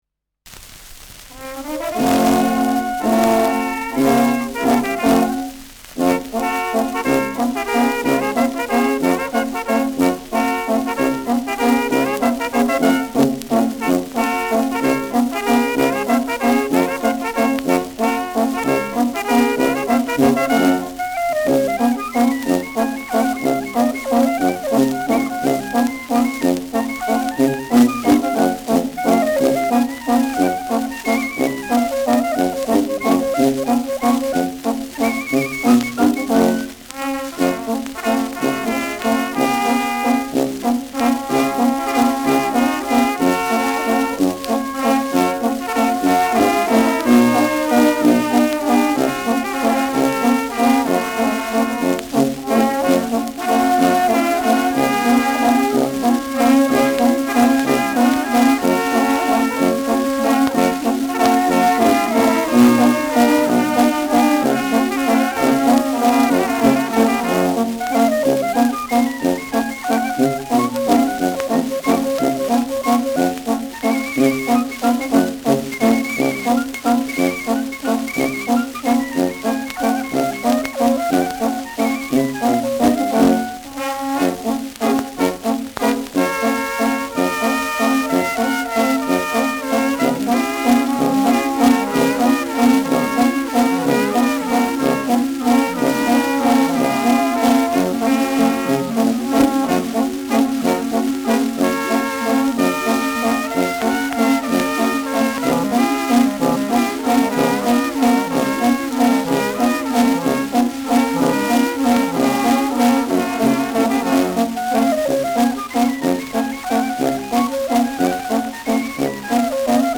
Schellackplatte
leichtes Rauschen : präsentes Knistern : abgespielt : leichtes Leiern
Dachauer Bauernkapelle (Interpretation)